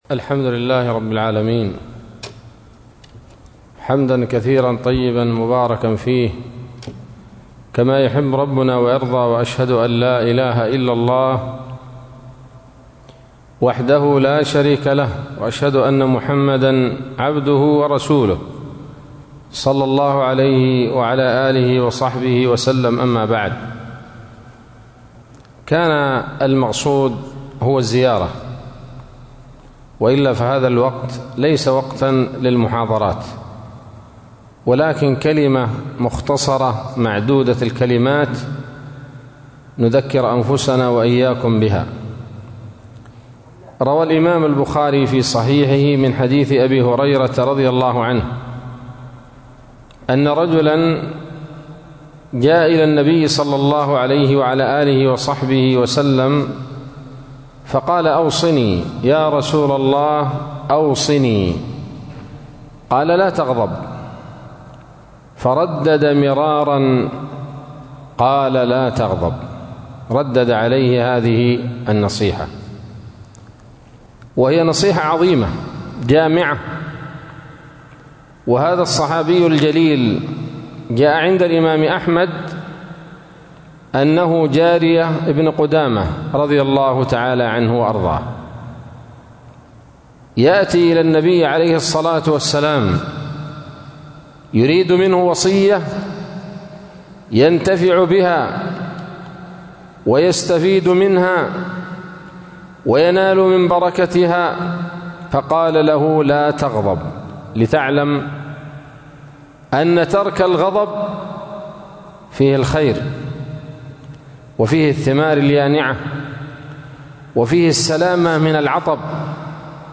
كلمة قيمة بعنوان: ((الغضب سبب الشقاء والعطب )) عصر الجمعة 13 جمادى الأولى 1446هـ، بمسجد درة عدن - عدن